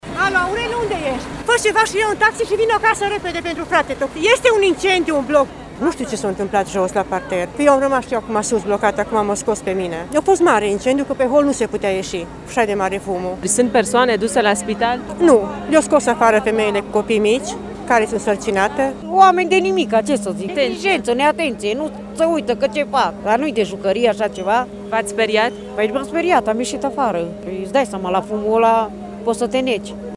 Vecinii spun că la interior se aflau aproximativ 30 de persoane.
incendiu-vox.mp3